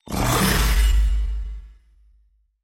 Откройте для себя коллекцию звуков нового уровня — инновационные аудиоэффекты, футуристические мелодии и необычные композиции.